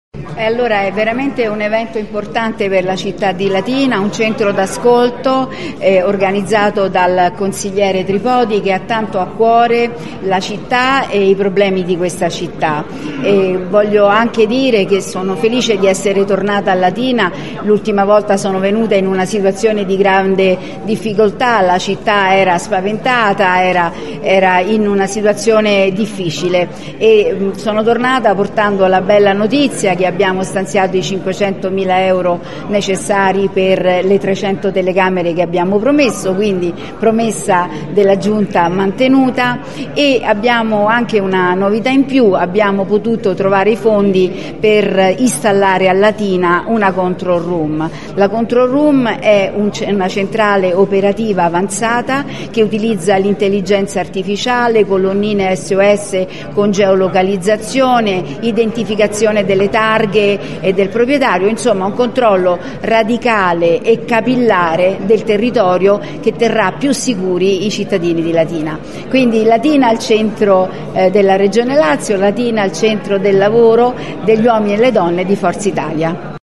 Le voci di Gasparri e Regimenti al microfono